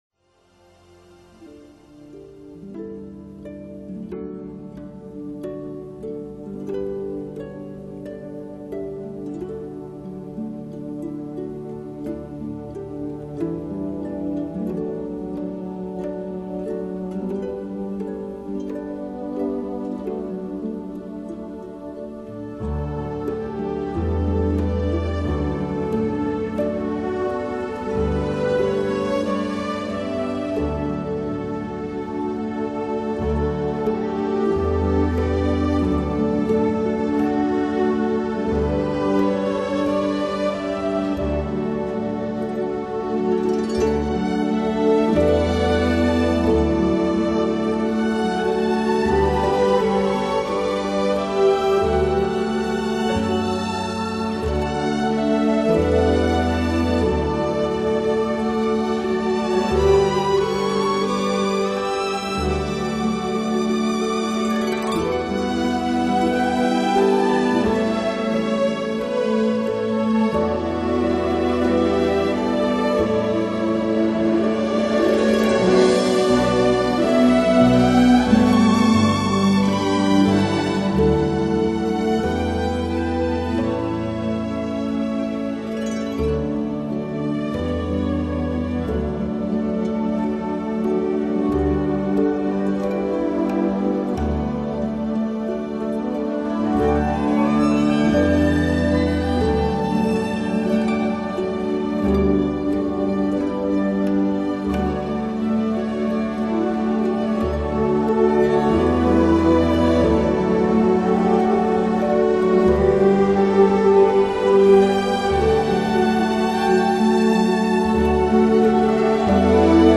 这种音乐超脱古典、爵士、流行，突破传统演奏与新世纪音乐间的隔阂。
行云流水般的电子小提琴，清澈悠扬